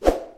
Звуки переходов
Взмах и переход